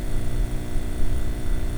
lampsound_loop.wav